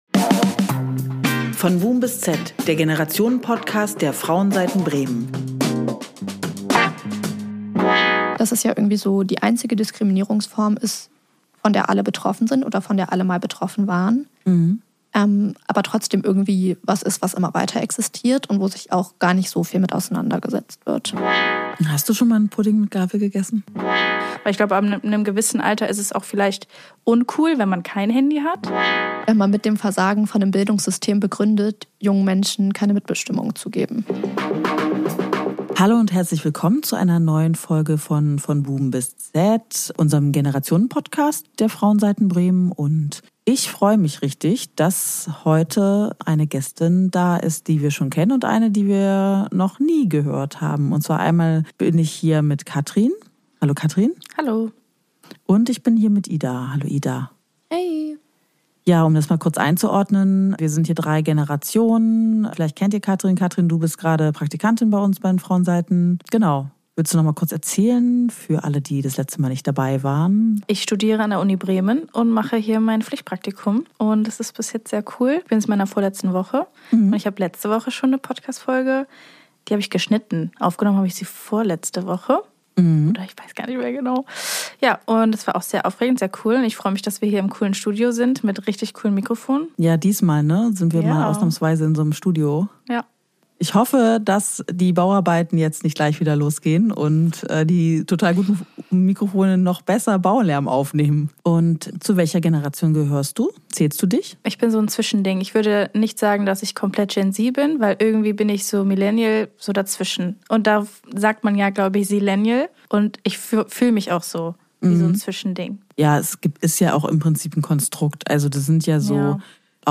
Die aktuelle Folge Von Boom bis Z ist einfach ein Generationengespräch...